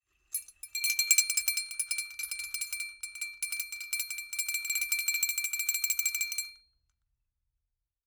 Small Bell Jingle.mp3